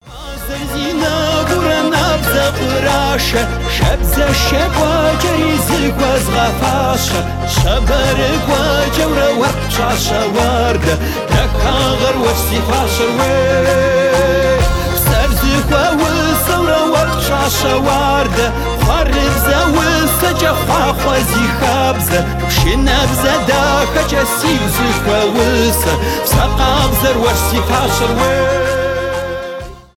кавказские , русские , поп